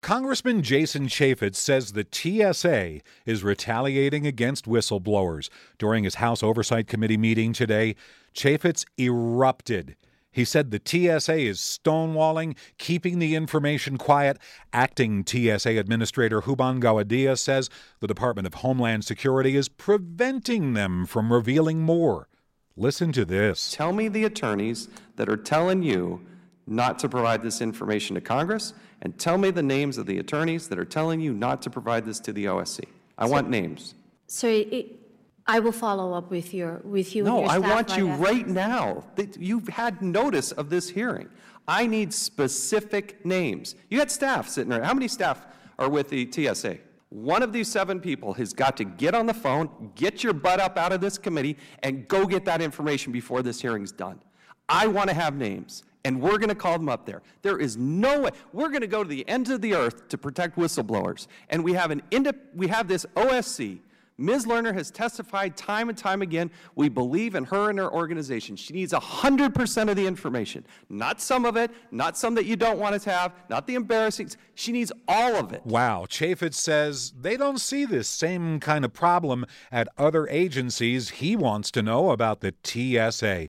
Utah Congressman Jason Chaffetz grilled acting TSA Administrator Huban Gowadia about why the agency is slow at helping out in whistle blower investigations. And Chaffetz wasn't happy when she wanted to delay getting information to the House Oversight Committee.